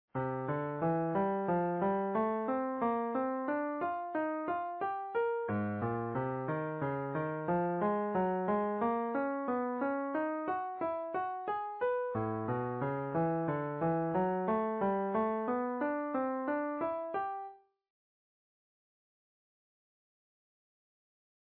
12 key cycle / major pentatonic idea. In this next idea, we simply outline the first four pitches of the pentatonic scale in quarter notes and move through an ascending cycle of fourths.